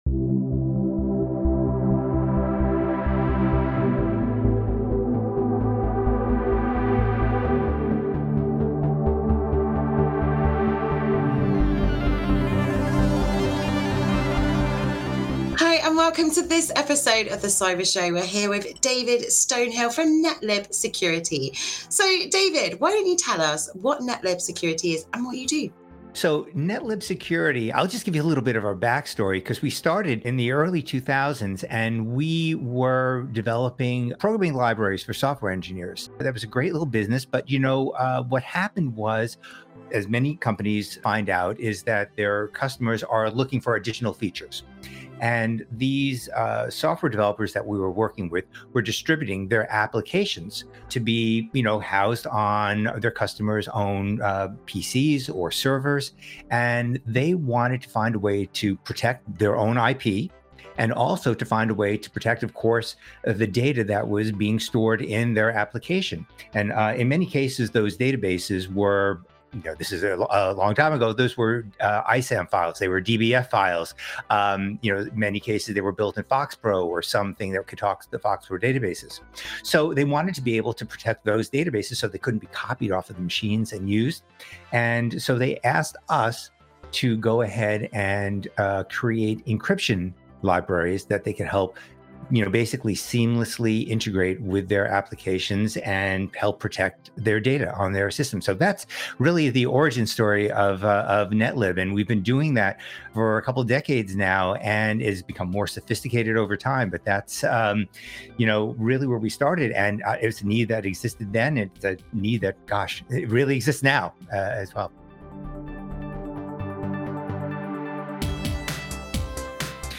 Categories: Audio Only In The Chair Interview Free Open Source Software Health and Technology Privacy, Dignity, Personal Data Distributed and Federated Systems Science, Research, Research Methods